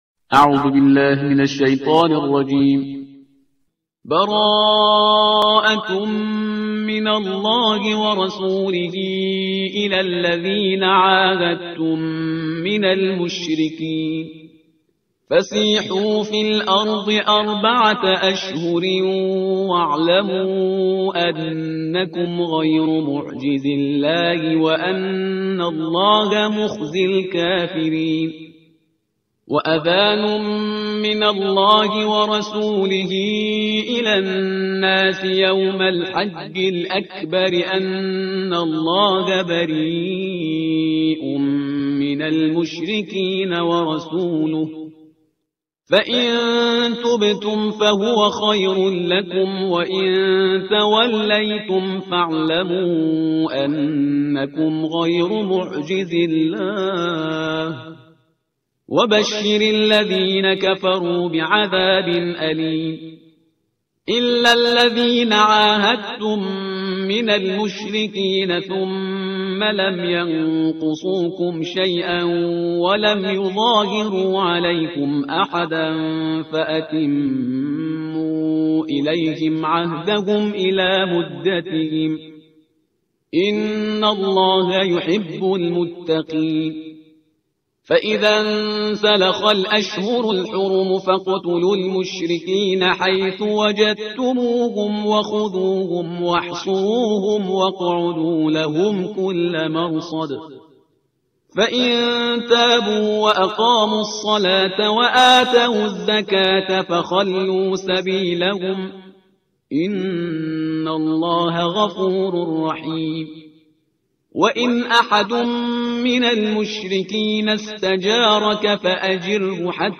ترتیل صفحه 187 قرآن با صدای شهریار پرهیزگار